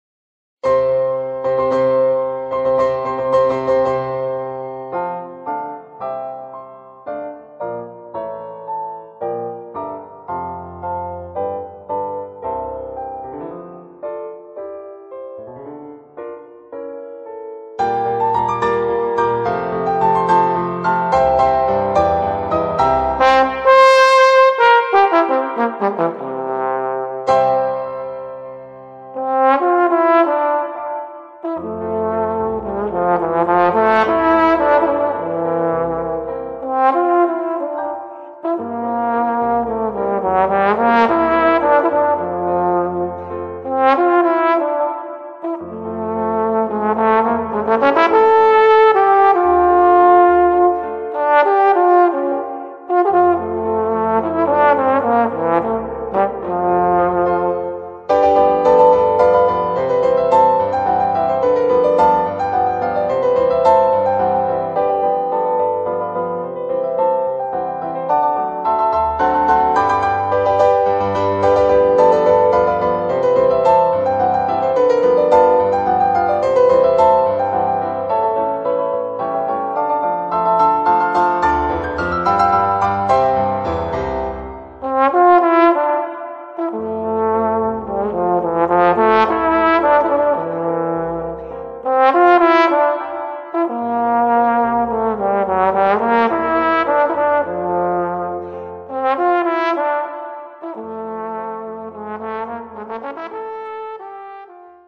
Voicing: Trombone w/ Audio